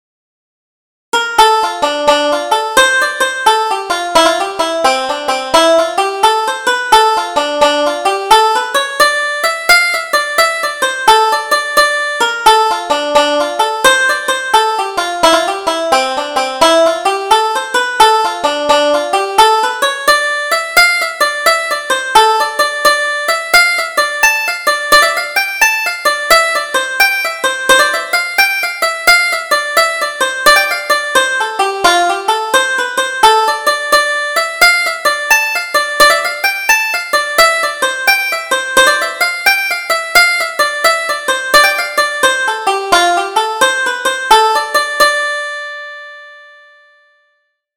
Double Jig: A Trip to Galway